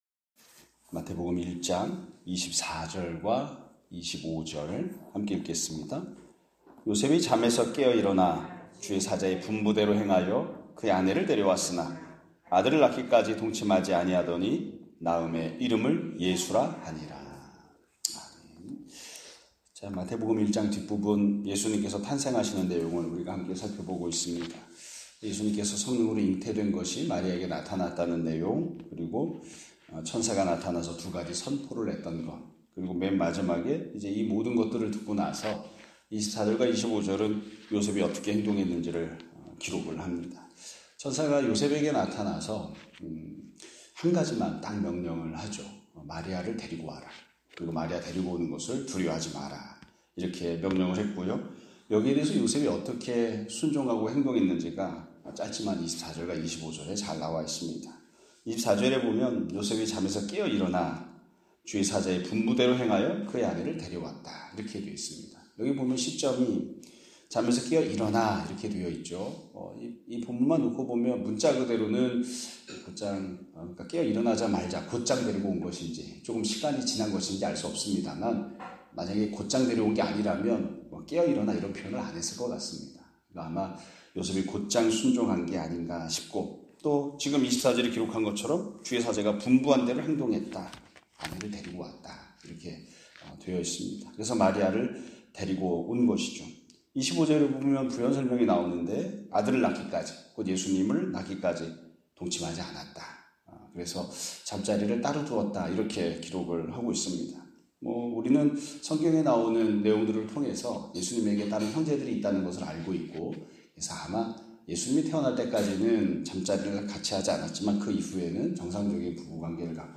2025년 3월 27일(목요일) <아침예배> 설교입니다.